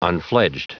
Prononciation du mot unfledged en anglais (fichier audio)
Prononciation du mot : unfledged